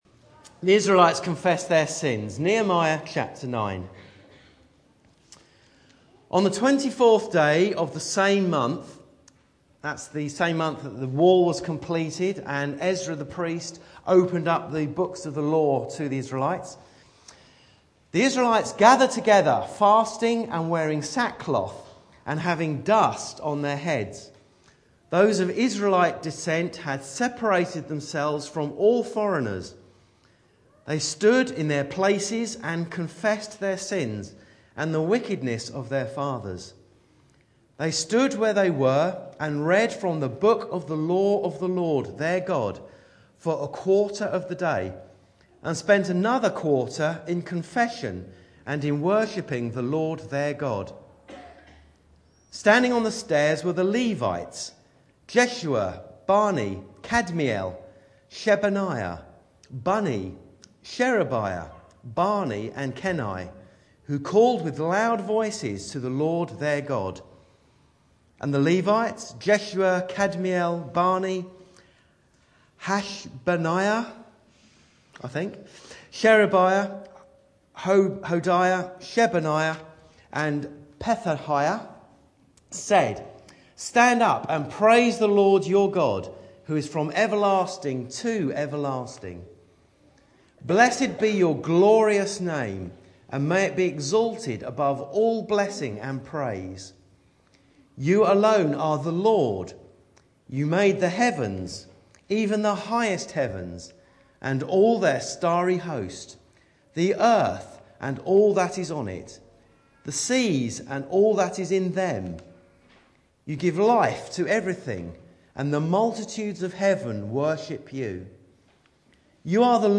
Back to Sermons Corporate Confession